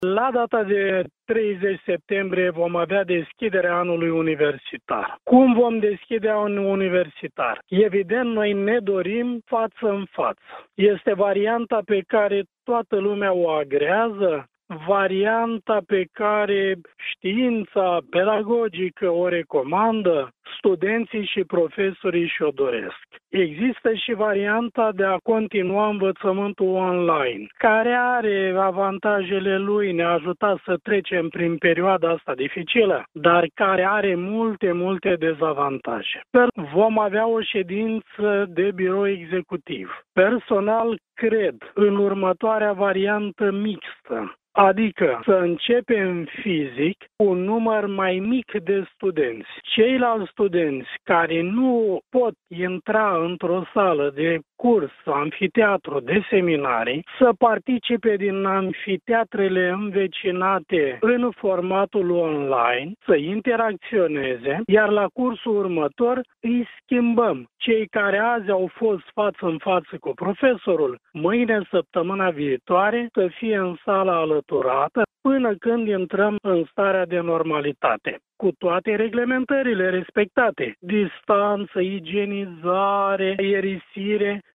El a declarat, pentru postul nostru de radio, că scenariul pe care îl preferă este cel în care studenţii revin în universitate, respectă normele de igienă şi de distanţare fizică, iar în cazul în care amfiteatrele sau sălile de seminar sunt pline, o parte dintre aceştia să participe online.